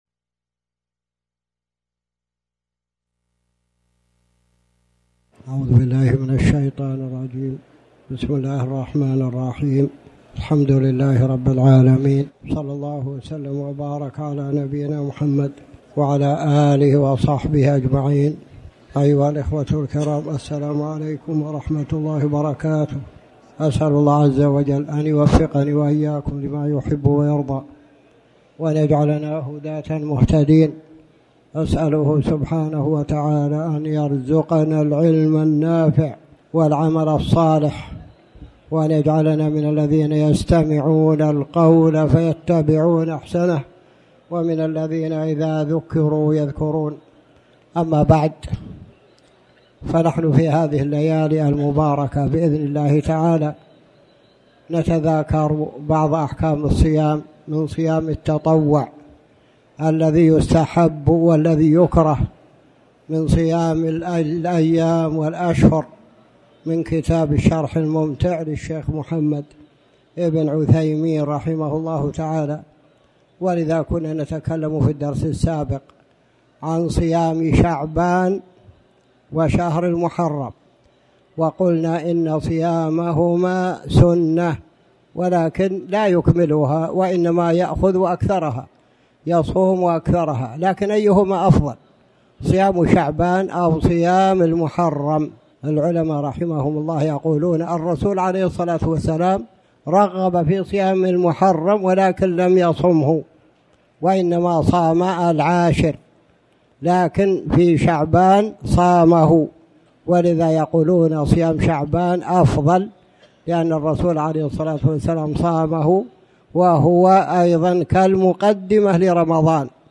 تاريخ النشر ١٥ شعبان ١٤٣٩ هـ المكان: المسجد الحرام الشيخ